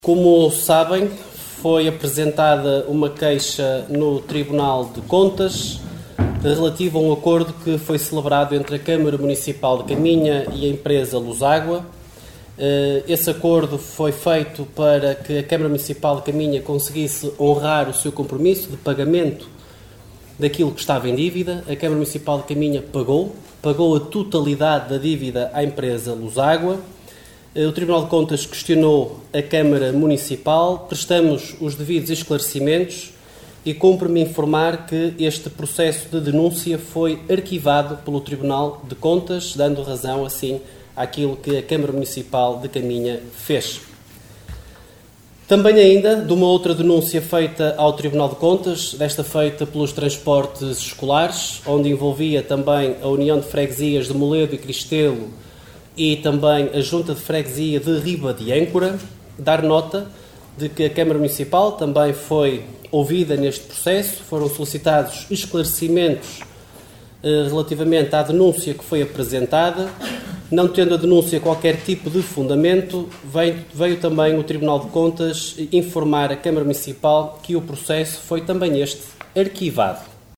Excertos da última reunião do executivo.